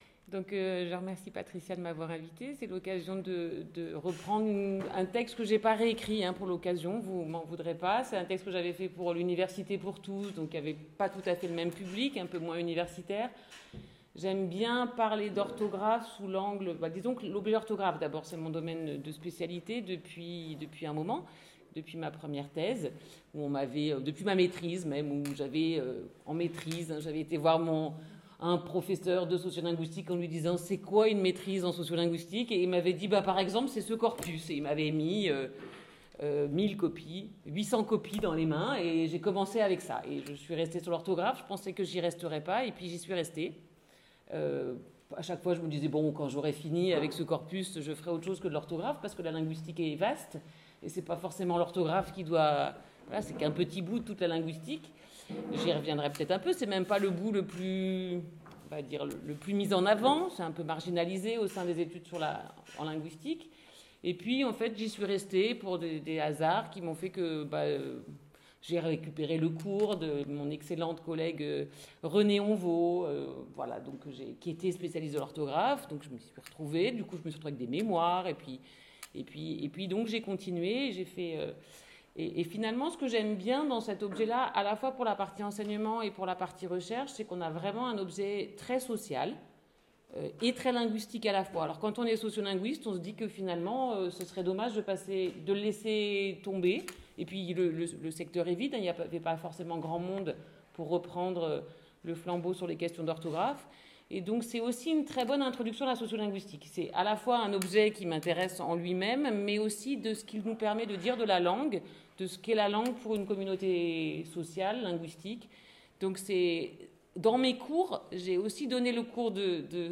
[Conférence] L’objectif de cette communication est de parler d’orthographe en tant qu’objet linguistique mais aussi en tant qu’objet social, l’un ne pouvant se faire sans l’autre.